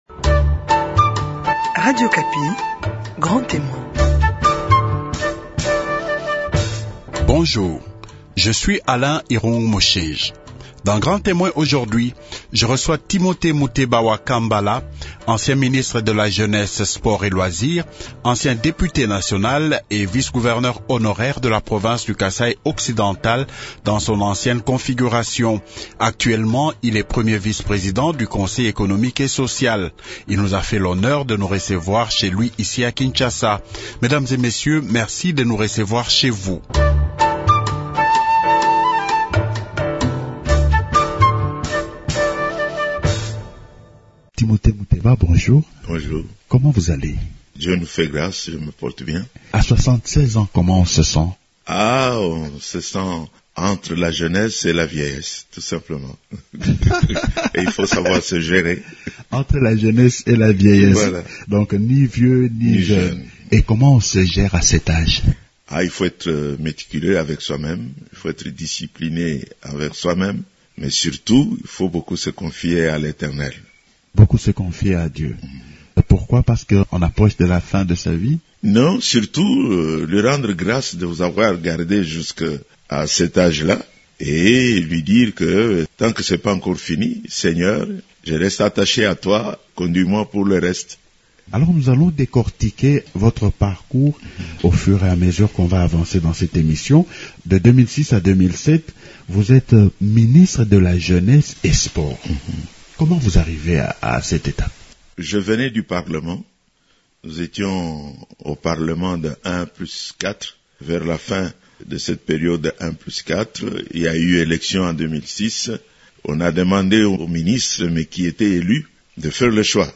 Grand Témoin reçoit Timothée Muteba wa Kambala, ancien ministre de la jeunesse sport et loisirs, ancien député national et vice-gouverneur honoraire de la province du Kasaï Occidental dans son ancienne configuration. Actuellement, il est premier vice-président du Conseil économique et social.